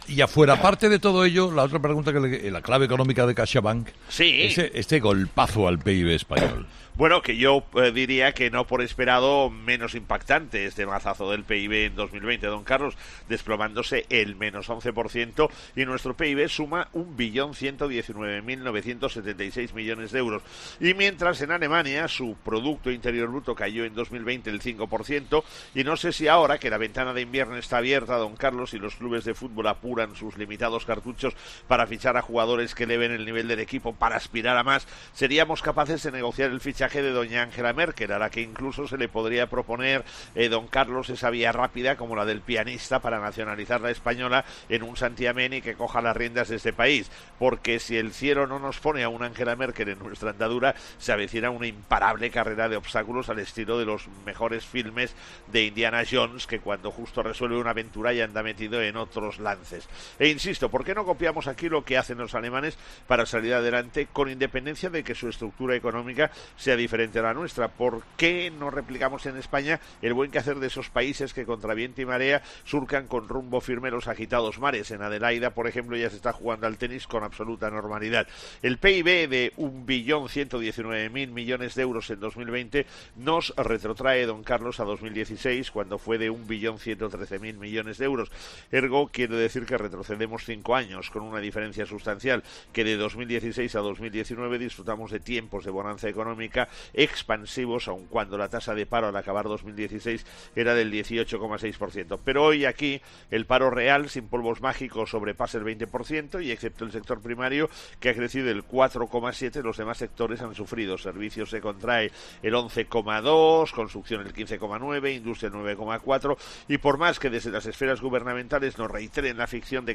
El profesor José María Gay de Liébana analiza en 'Herrera en COPE’ las claves económicas del día